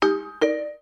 Genel olarak telefonun güçlü özelliklerini yansıtan polifonik ve güçlü tonların bulunduğu bildirim seslerinin firmanın yıllardır süregelen seslerin modern yorumlarını içermektedir.
Buzz
buzz.mp3